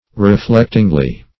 Meaning of reflectingly. reflectingly synonyms, pronunciation, spelling and more from Free Dictionary.
Search Result for " reflectingly" : The Collaborative International Dictionary of English v.0.48: Reflectingly \Re*flect"ing*ly\, adv.